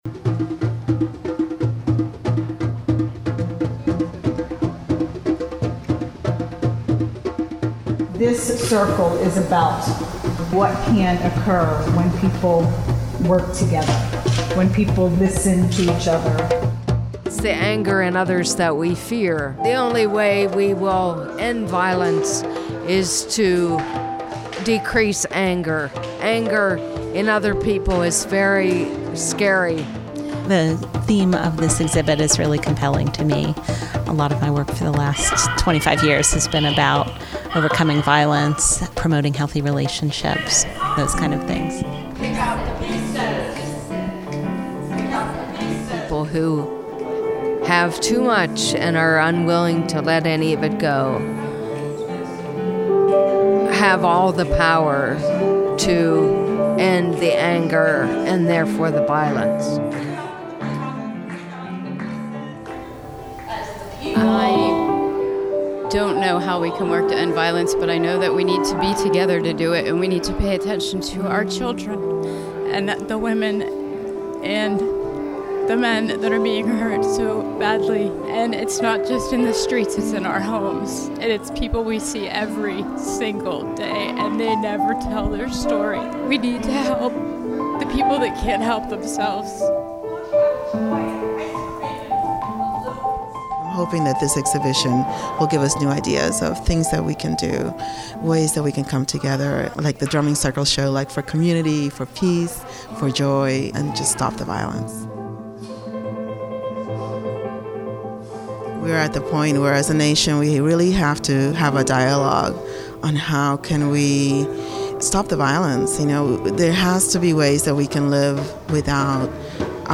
ENOUGH Violence: Artists Speak Out opening reception
Hear visitors, artists and Contemporary Craft staff react to the art and the exhibition below.